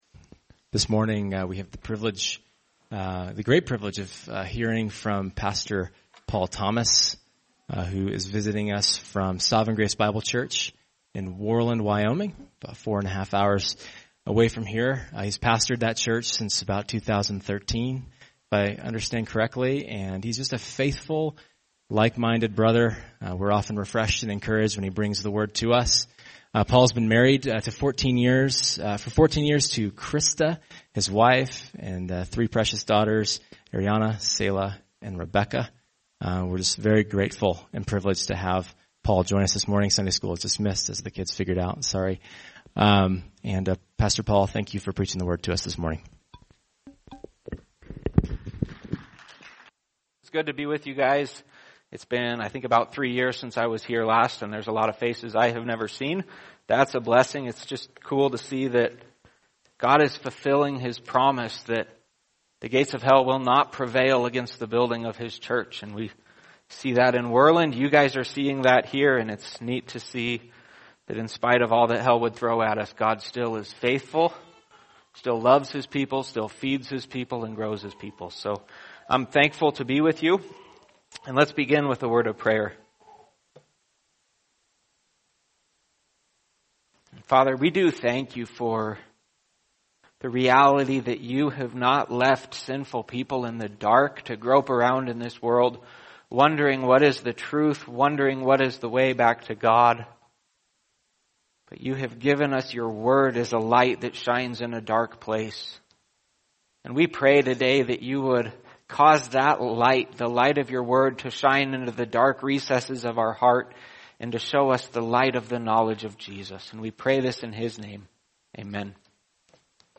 [sermon] 2 Peter 1:5 Balancing Sanctification | Cornerstone Church - Jackson Hole